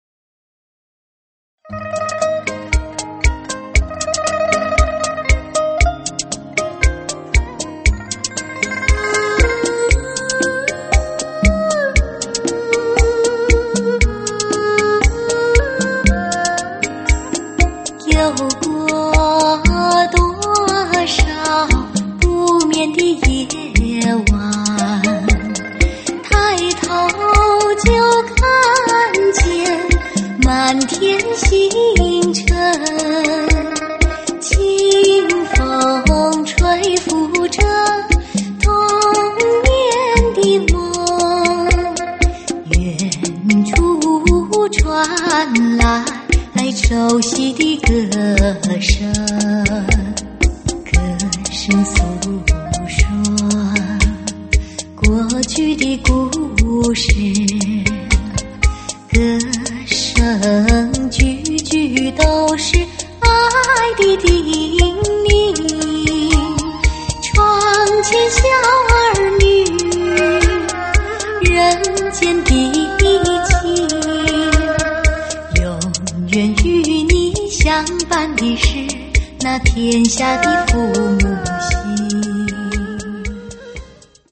伦巴舞曲